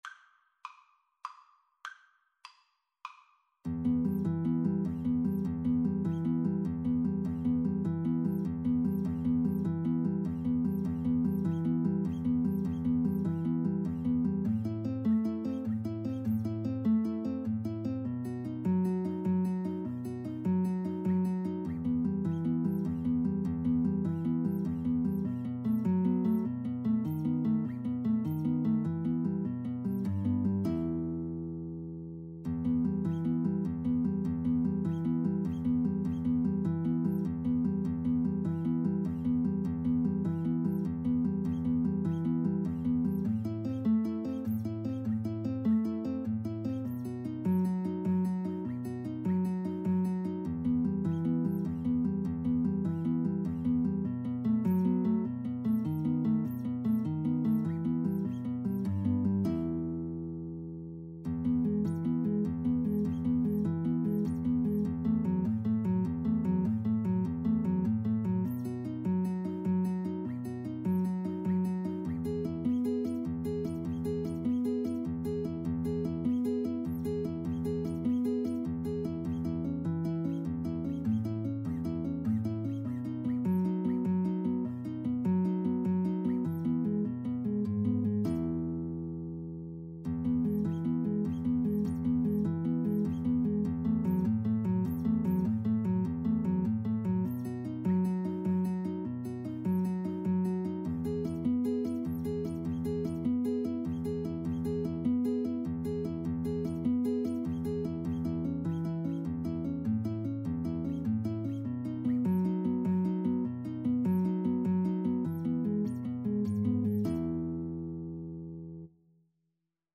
Free Sheet music for Violin-Guitar Duet
3/4 (View more 3/4 Music)
G major (Sounding Pitch) (View more G major Music for Violin-Guitar Duet )
Traditional (View more Traditional Violin-Guitar Duet Music)